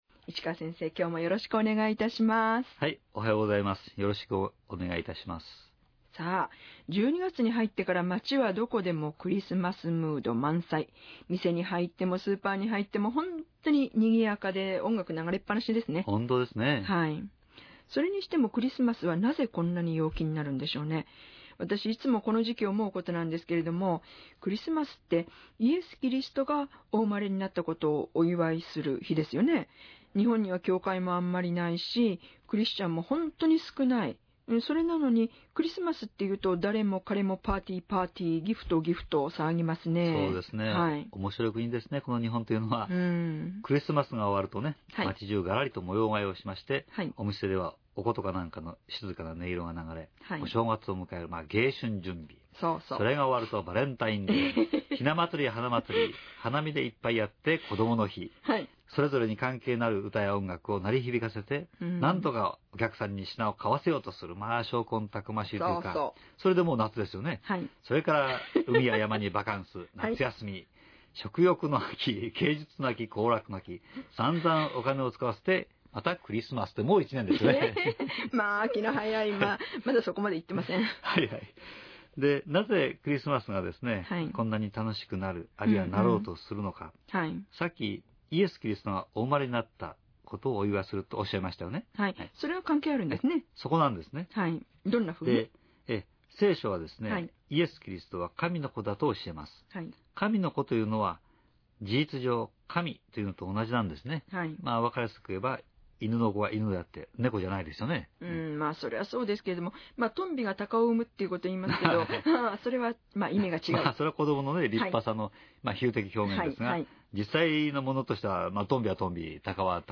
ラジオ番組